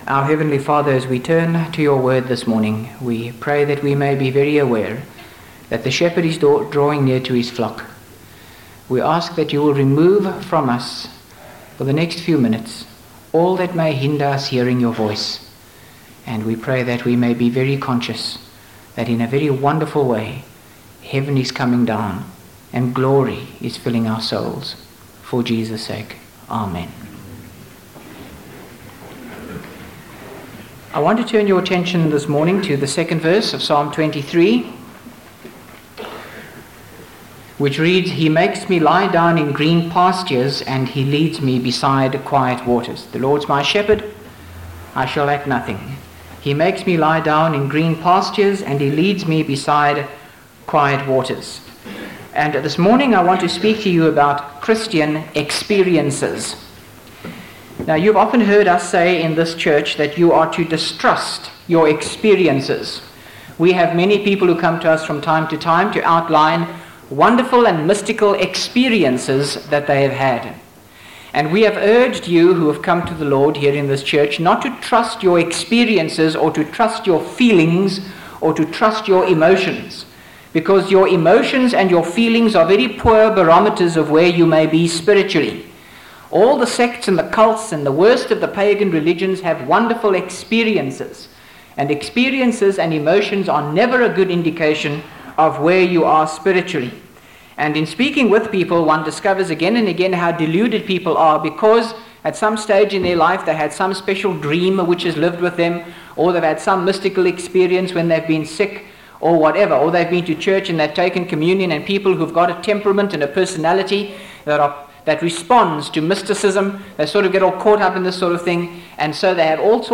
by Frank Retief | Feb 3, 2025 | Frank's Sermons (St James) | 0 comments